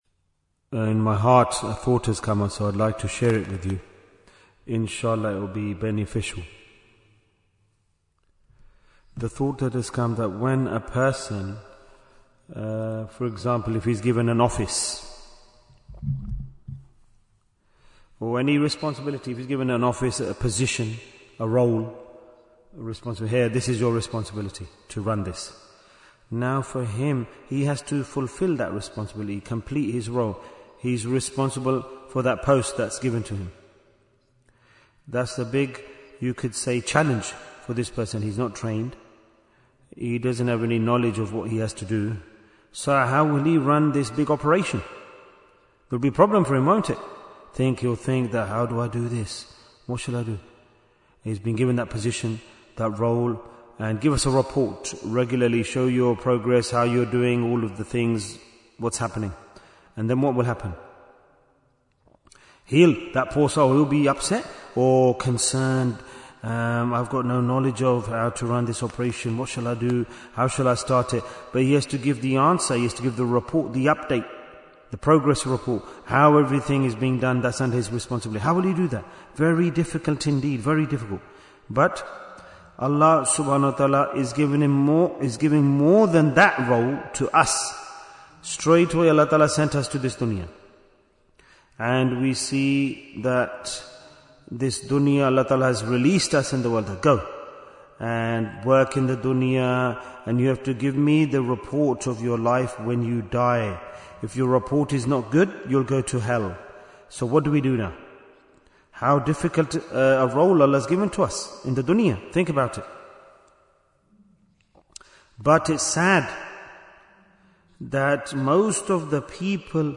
- Part 7 Bayan, 37 minutes 19th January, 2026 Click for Urdu Download Audio Comments Why Is Tazkiyyah Important? Part 7 We are not in this world to remain forever.